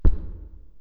Add dino footstep sound
dino-step.wav